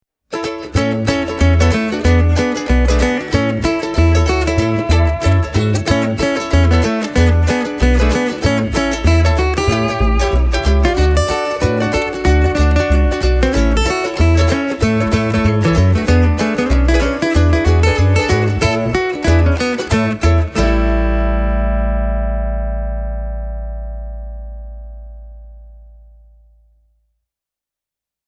I have recorded three audio examples to give you an idea of the modelling technology’s sound: